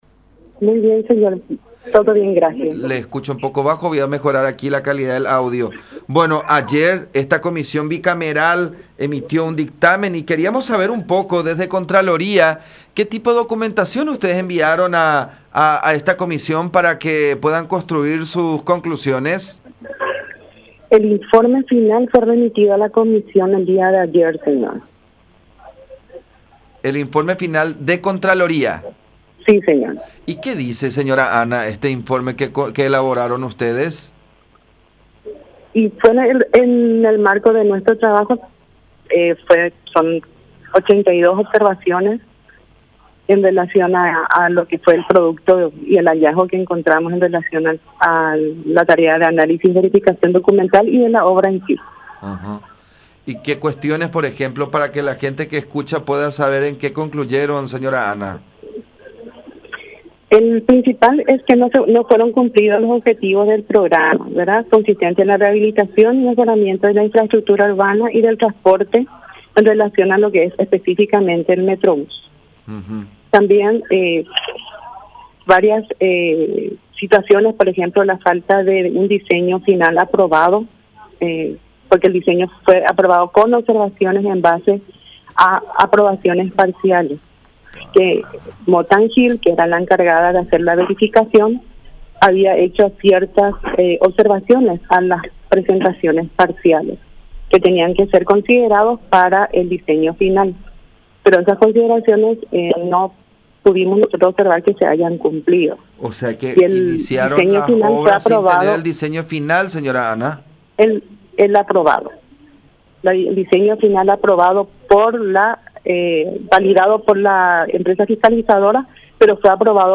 en diálogo con La Unión